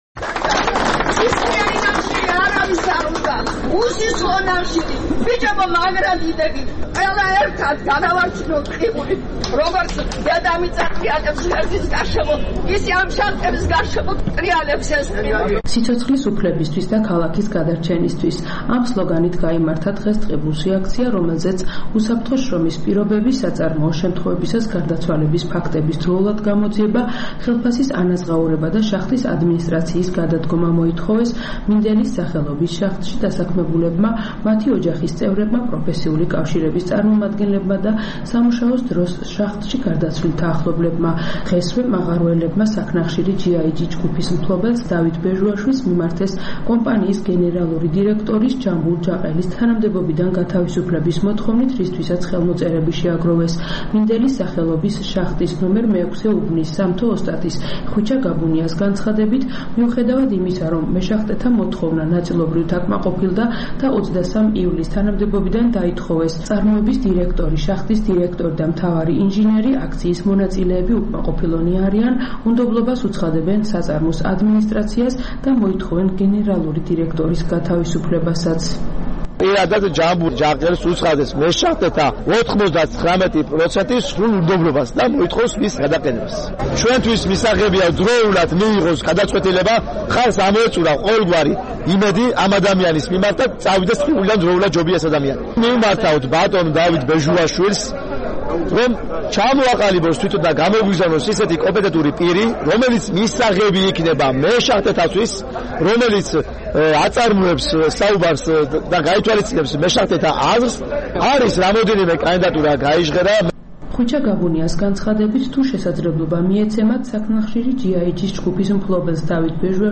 საპროტესტო აქცია ტყიბულში
აქცია ტყიბულში - სიცოცხლის უფლებისთვის